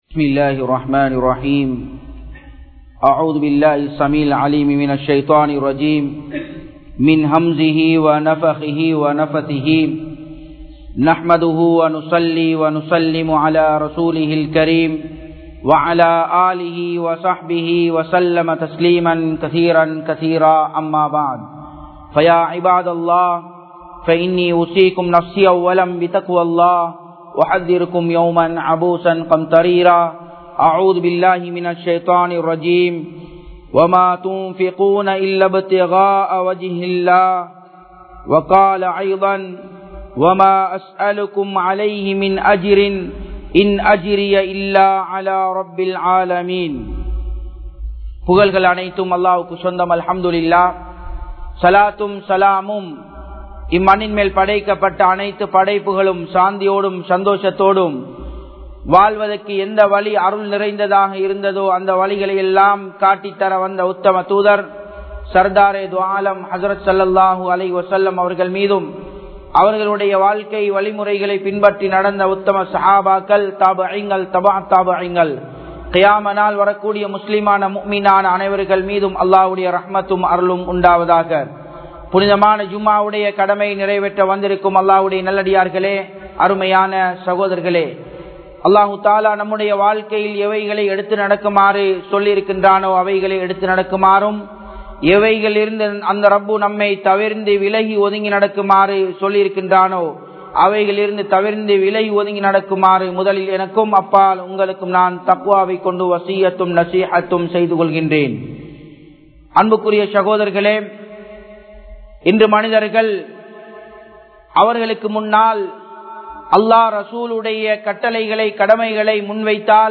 Allahvukkaaha Vaalvoam (அல்லாஹ்வுக்காக வாழ்வோம்) | Audio Bayans | All Ceylon Muslim Youth Community | Addalaichenai
Japan, Nagoya Port Jumua Masjidh 2017-06-30 Tamil Download